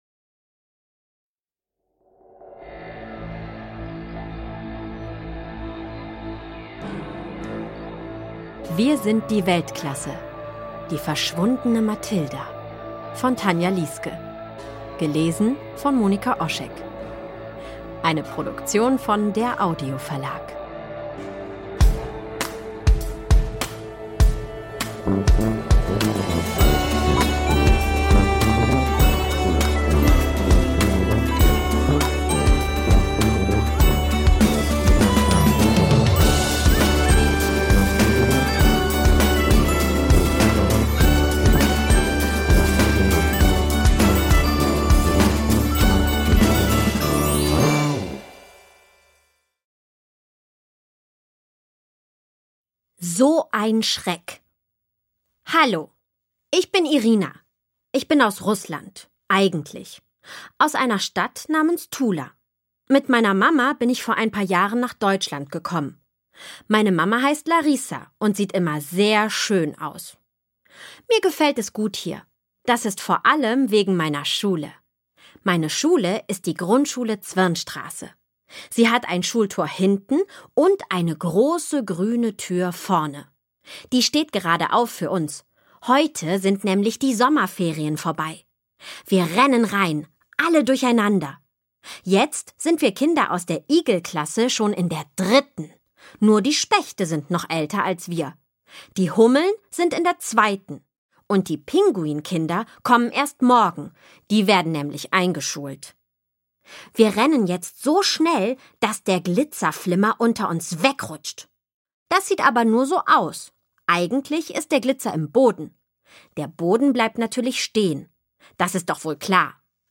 Ungekürzte szenische Lesung mit Musik